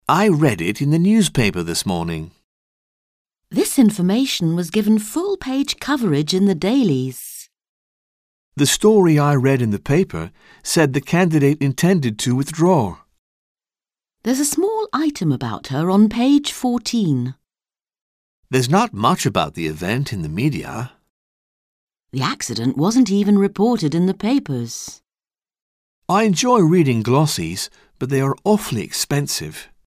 Un peu de conversation - La presse écrite